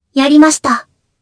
Kara-Vox_Happy4_jpb.wav